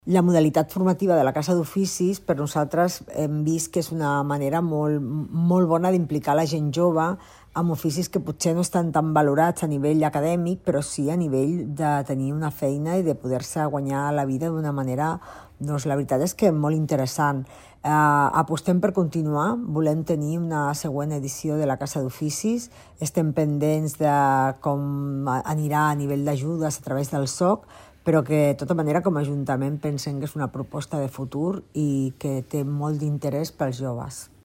Rosa Cadenas, regidora de Promoció Econòmica de l'Ajuntament